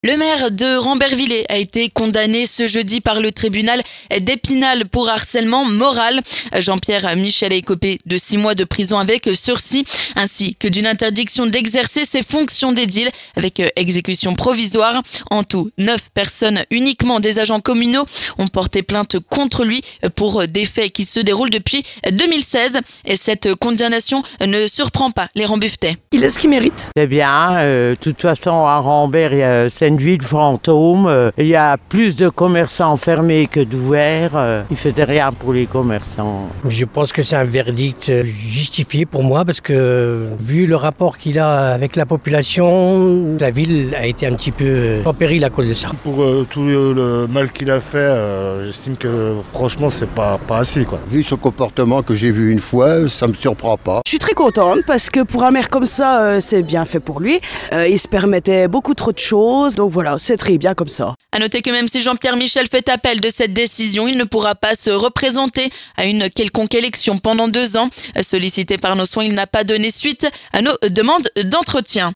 Le maire de Rambervillers condamné pour harcèlement moral, la réaction des habitants